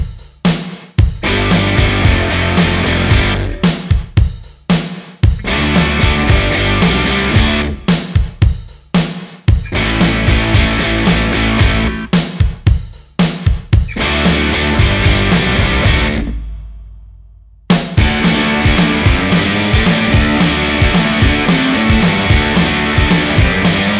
no Backing Vocals Punk 3:09 Buy £1.50